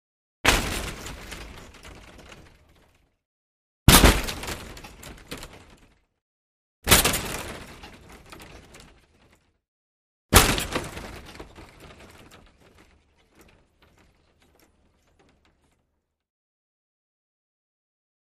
Hockey Player Hits Board
Hockey: Body / Board Impact ( 4x ); Hockey Player Impacting Plexiglas Wall; Four Times, Loud Low Frequency Impact / Long Sustaining Shaking Of Glass, Close Perspective.